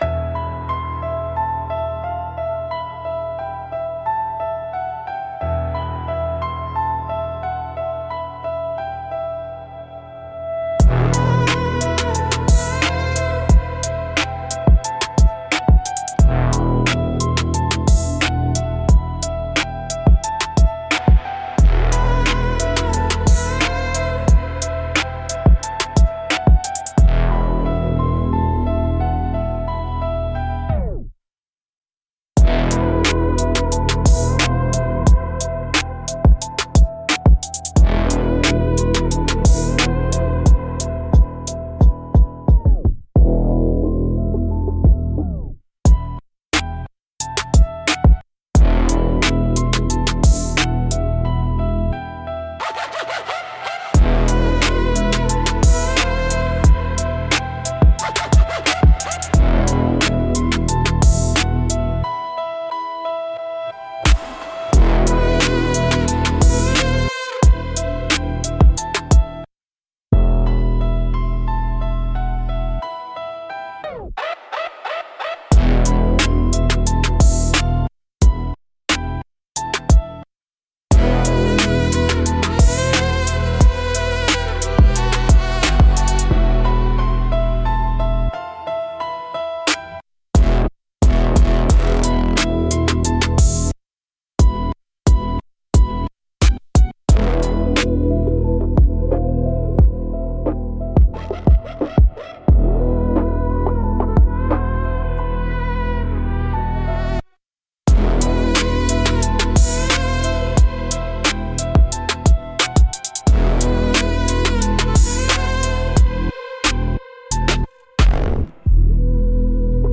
Instrumentals - Pressure Plate